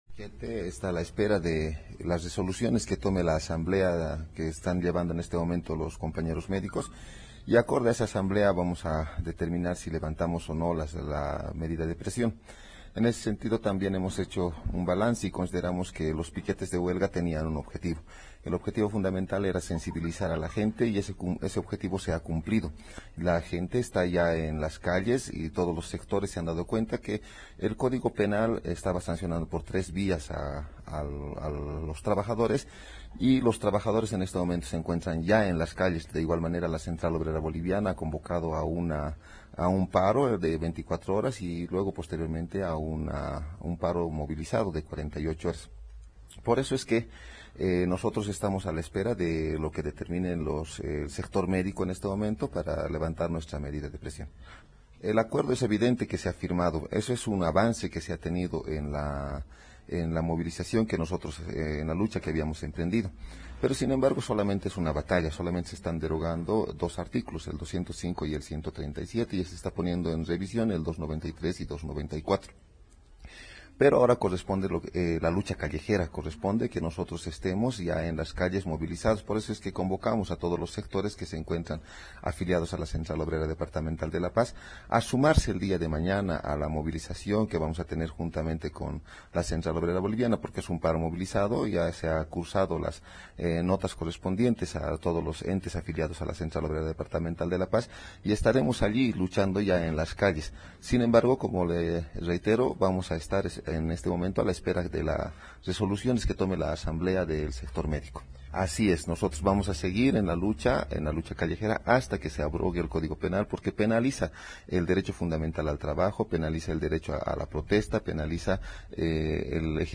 Declaración